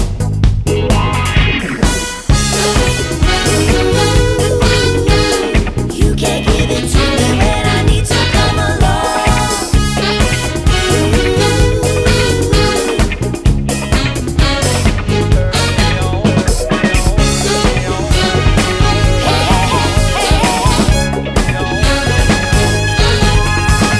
Live Version Pop (1960s) 3:32 Buy £1.50